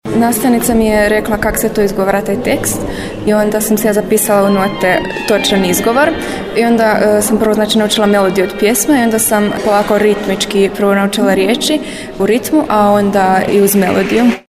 U Vijestima prenosimo insert sa sinoćenjeg koncerta u Dvorcu